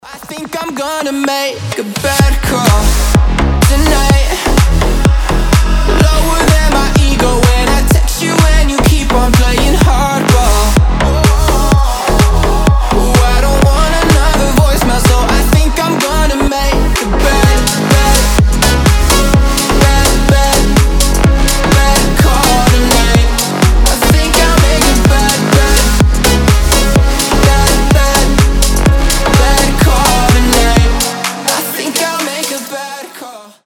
• Качество: 320, Stereo
громкие
EDM
slap house